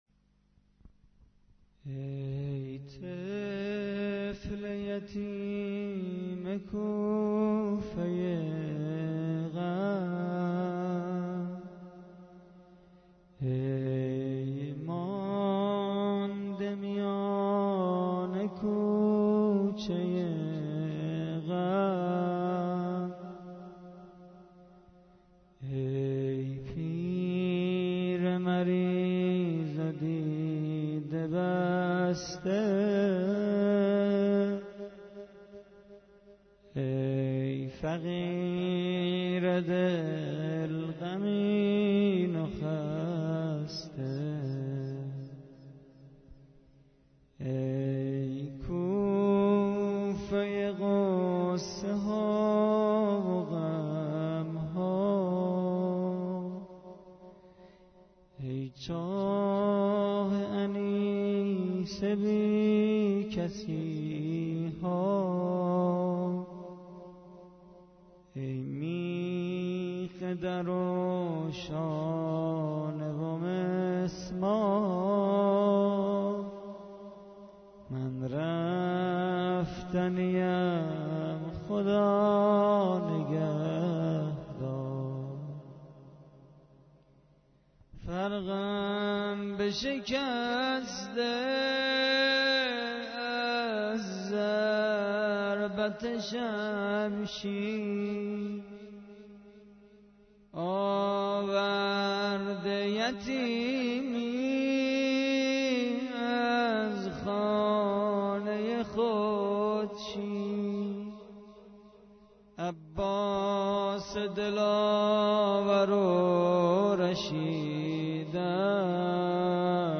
متن مداحی شب بیست و یکم ماه رمضان به سبک زمزمه -( ای طفل یتیم کوفه ی غم )